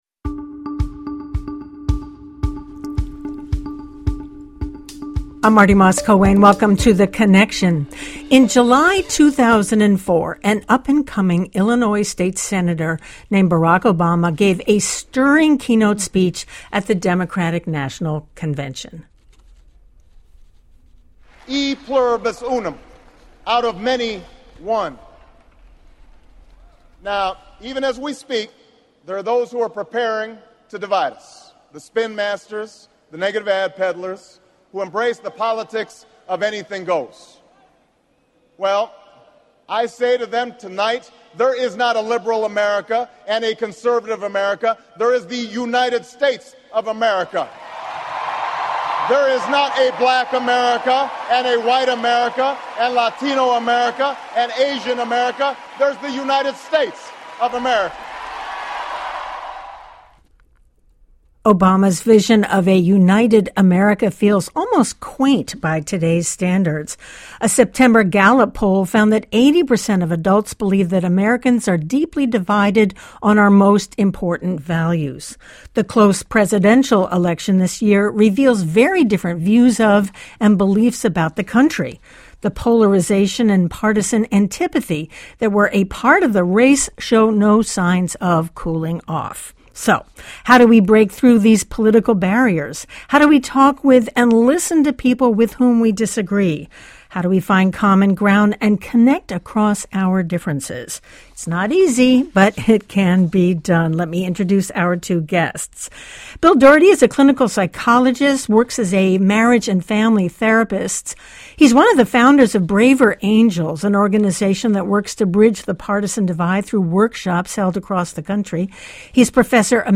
Governor Christie speaking to the full Legislature in Trenton on Tues, Feb 28, 2017.